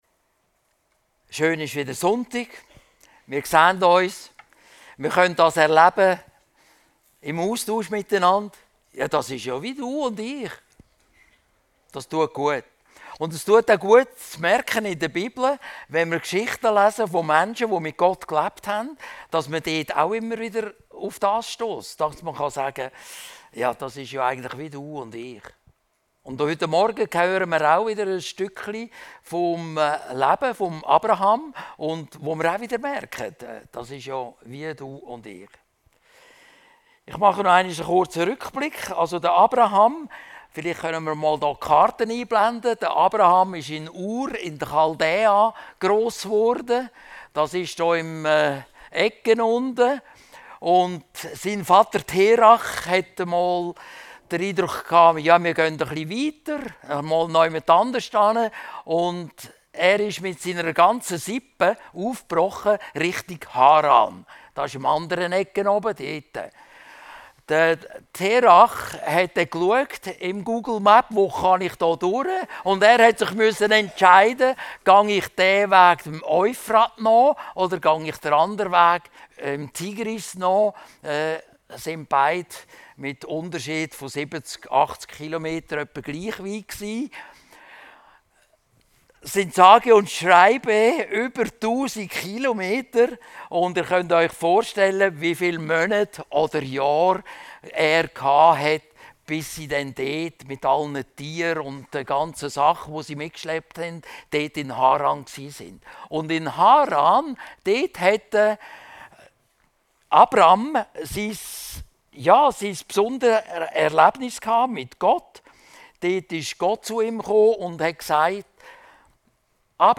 Abram im Clinch - seetal chile Predigten